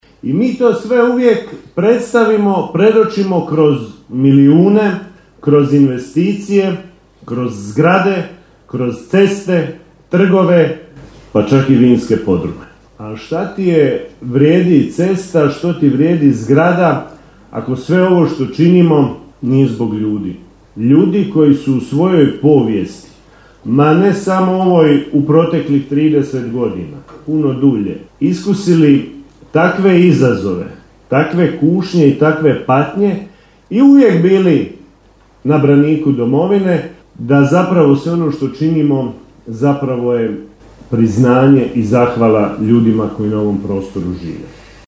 Svečana sjednica Gradskog vijeća Pakraca upriličena je u povodu Dana Grada Pakraca u Hrvatskom domu dr. Franjo Tuđman u Pakracu.
Kako je u svom govoru primijetio ministar Marin Piletić nema segmenta u Pakracu koji nije obuhvaćen projektima, naporima i iskoracima u proteklih godinu dana